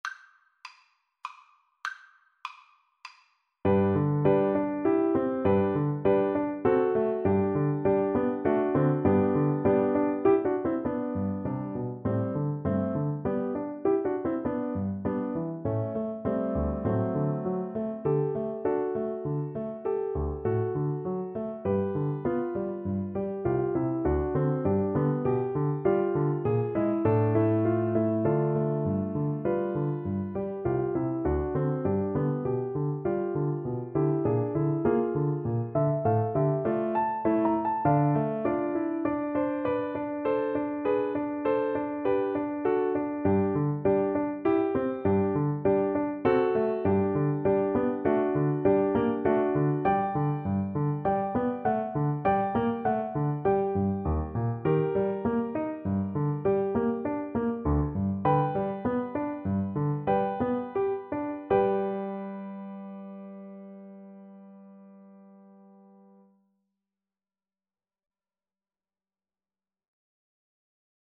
3/4 (View more 3/4 Music)
Classical (View more Classical Flute Music)